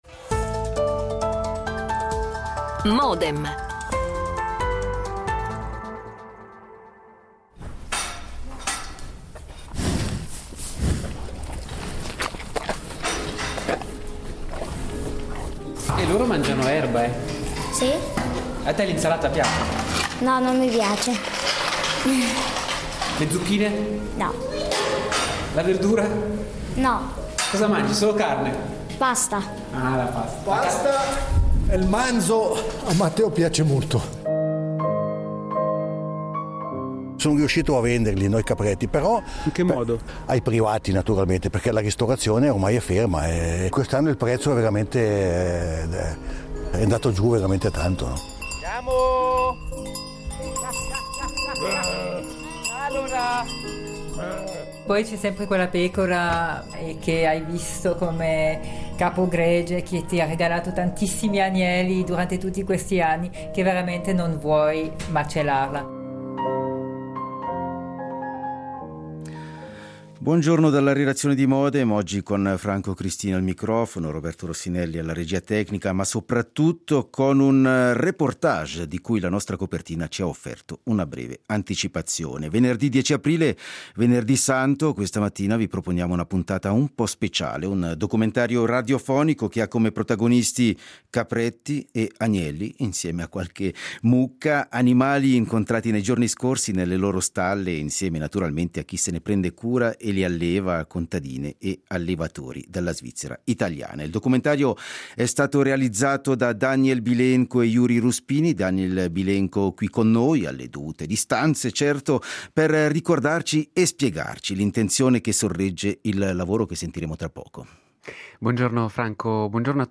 Protagonisti capretti e agnelli (insieme a qualche mucca), bestie da reddito incontrate in stalla insieme a chi se ne prende cura e li alleva, le contadine e gli allevatori della Svizzera italiana.